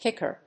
音節kíck・er 発音記号・読み方
/ˈkɪkɝ(米国英語), ˈkɪkɜ:(英国英語)/